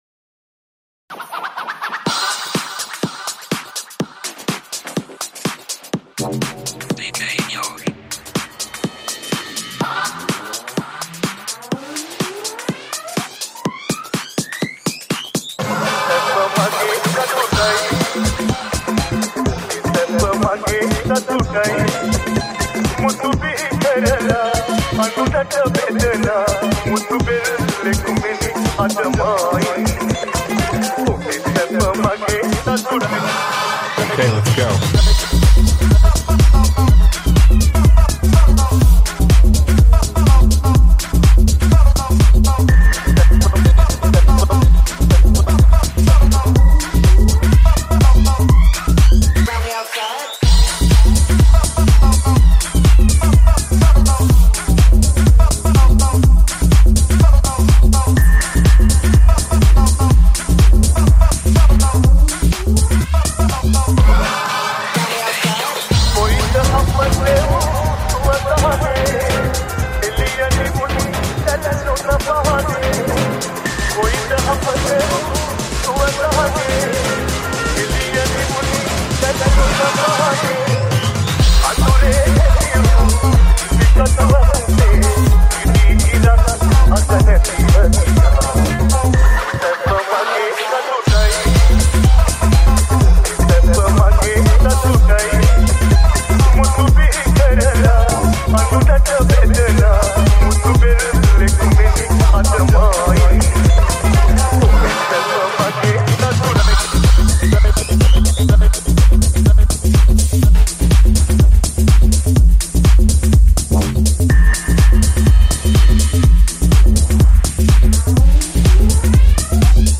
SL REMIX New Song
PUNJAB REMIX